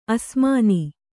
♪ asmāni